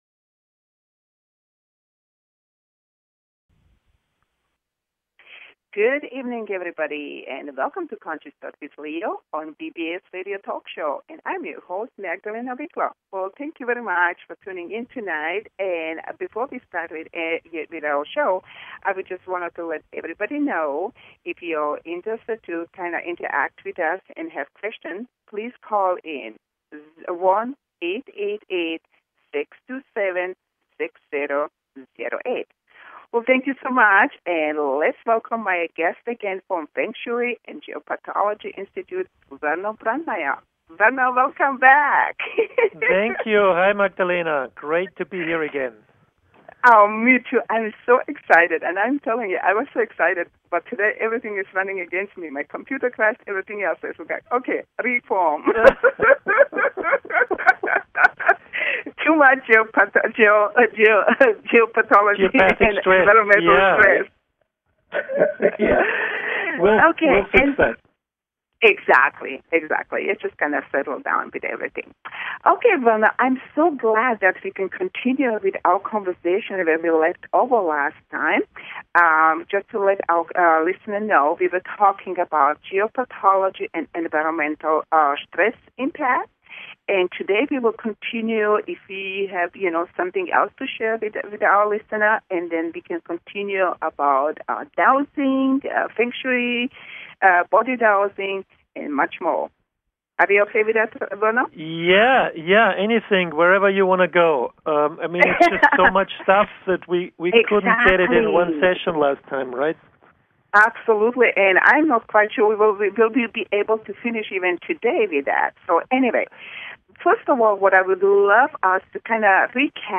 Talk Show Episode
PART 2: Interview